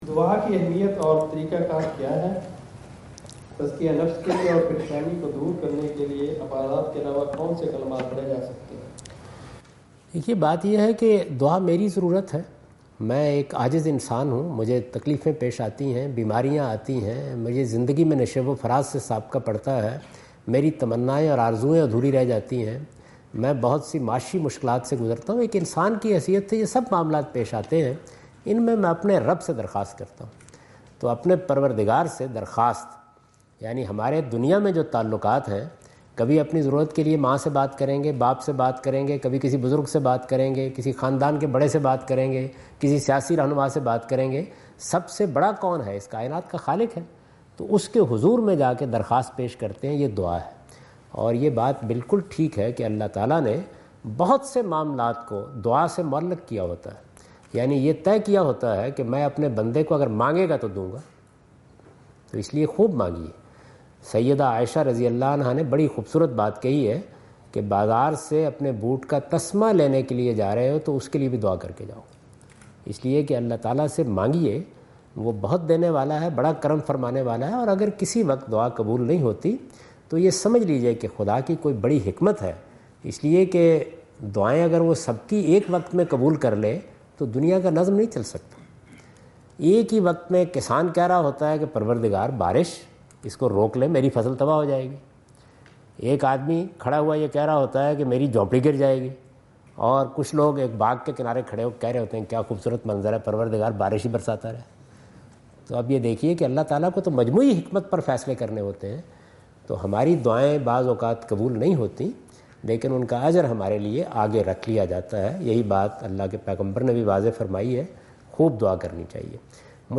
Javed Ahmad Ghamidi answer the question about "significance and method of supplication" in Macquarie Theatre, Macquarie University, Sydney Australia on 04th October 2015.